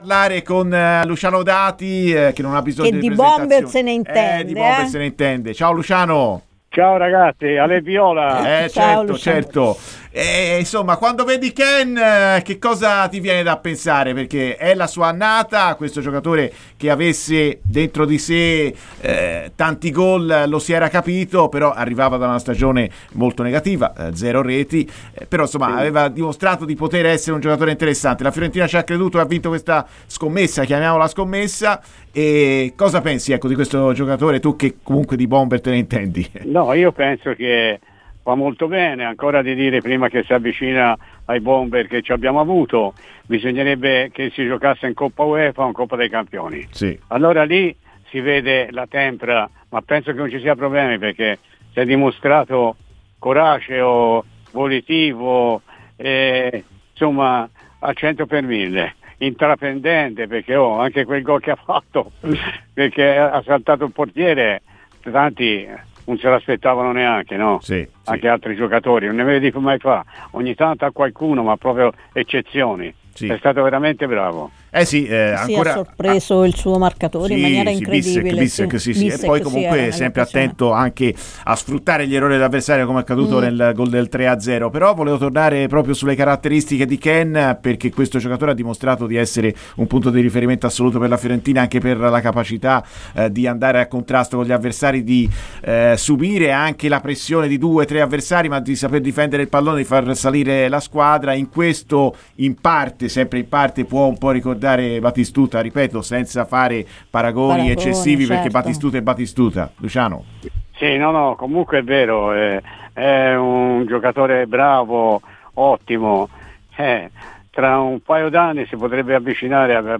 Con Batistuta ne ha parlato di questa Fiorentina?